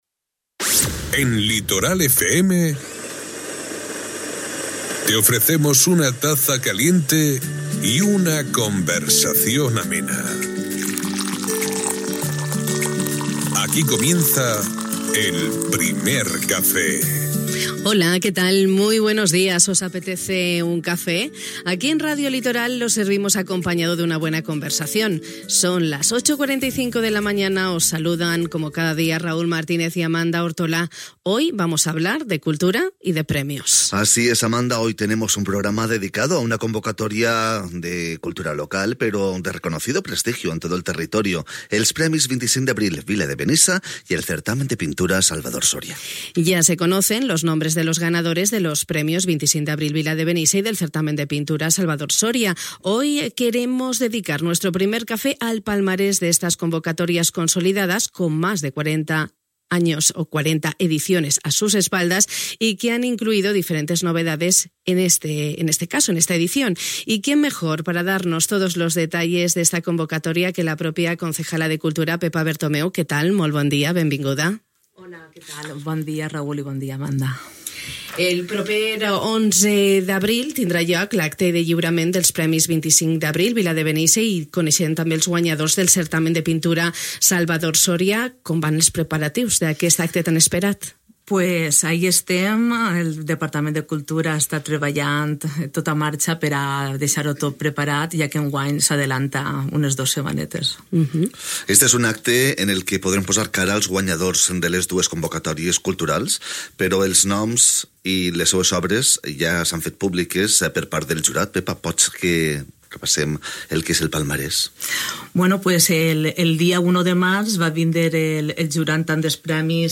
Avui hem dedicat el nostre Primer Cafè a repassar el palmarès d'aquestes convocatòries consolidades, amb més de 40 edicions a l'esquena, ia conèixer les diferents novetats que s'han inclòs en aquesta edició amb la regidora de Cultura, Pepa Bertomeu.